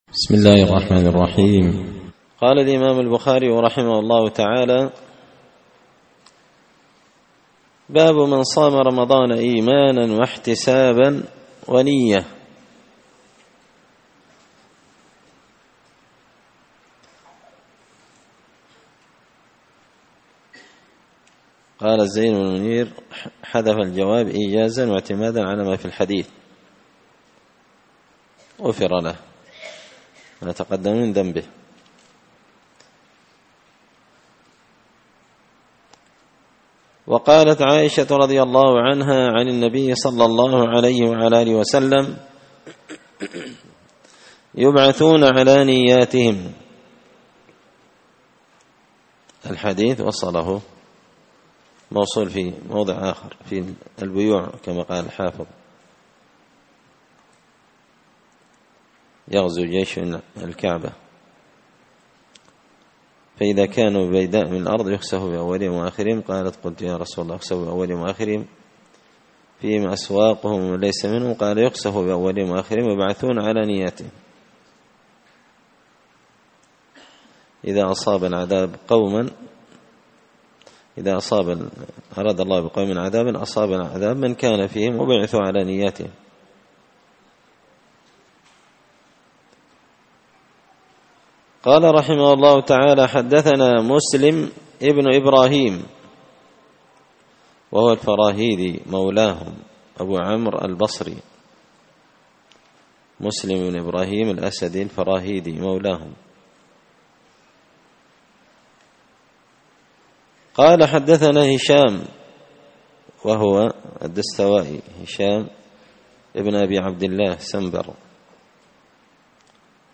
كتاب الصيام من صحيح البخاري الدرس السادس (6) باب من صام رمضان إيمانا واحتسابا ونية
مسجد الفرقان قشن_المهرة_اليمن